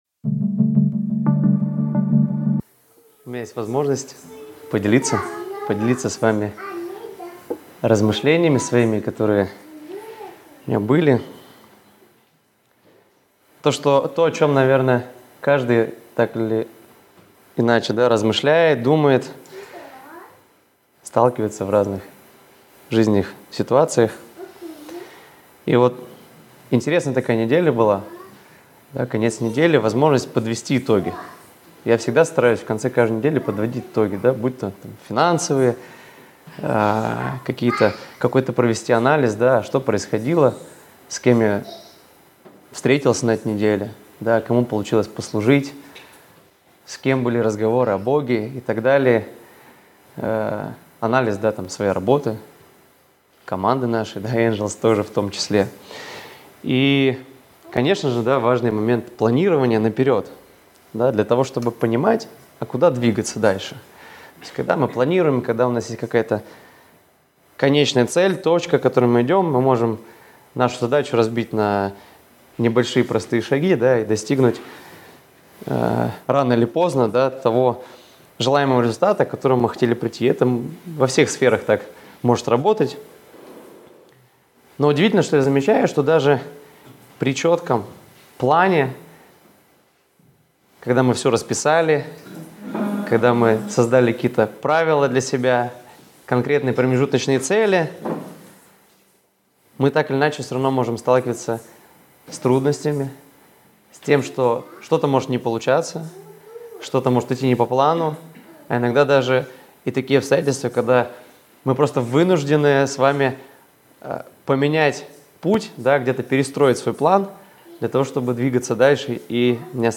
Воскресная проповедь - 2024-12-15 - Сайт церкви Преображение